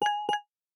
biometric_register_progressing.ogg